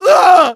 Voice file from Team Fortress 2 German version.
Medic_painsevere02_de.wav